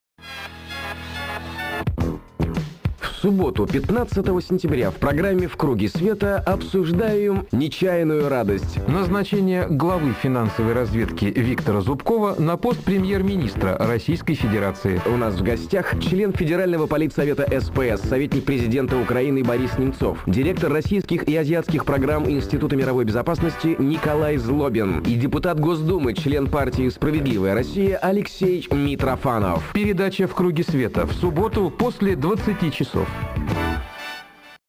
на радио «Эхо Москвы»
Аудио: анонс –